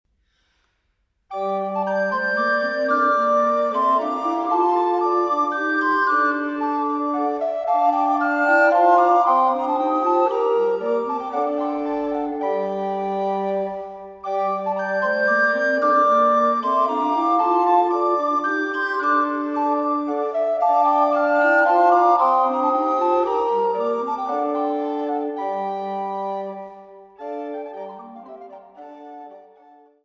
Fünfstimmiges Blockflötenconsort